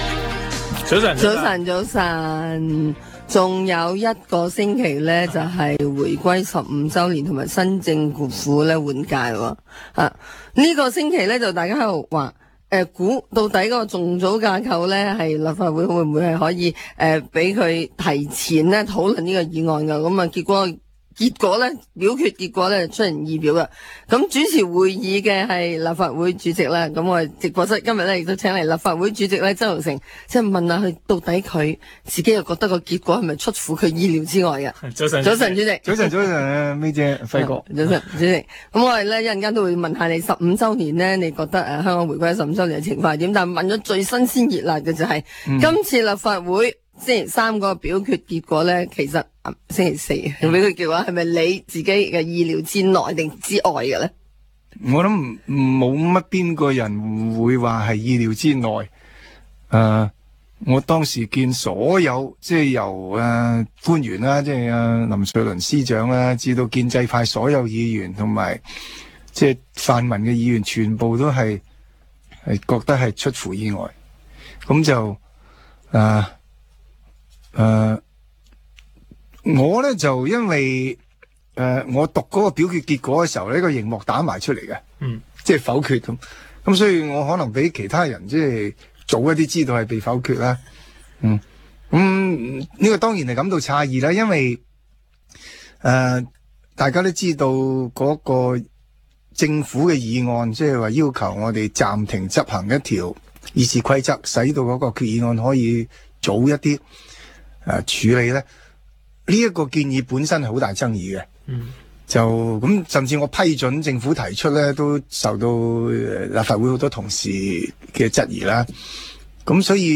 商業電台《政經星期六》訪問